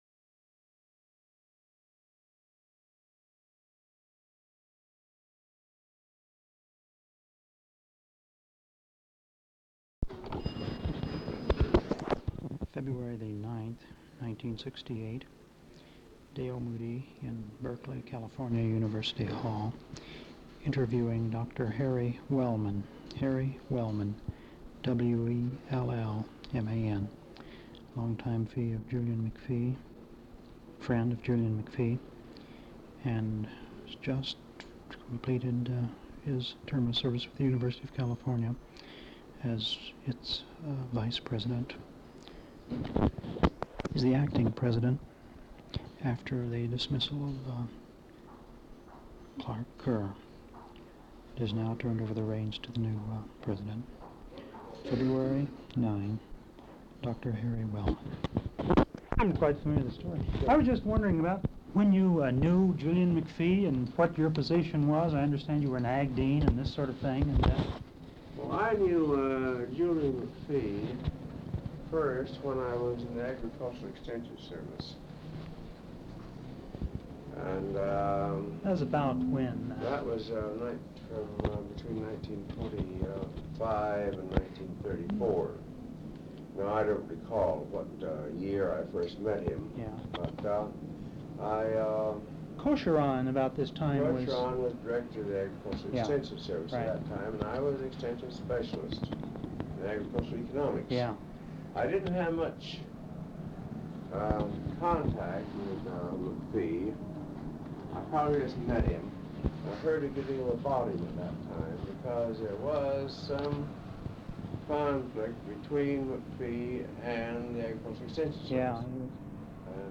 Interview with Harry R. Wellman
Form of original Open reel audiotape